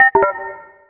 rmt_disconnect.wav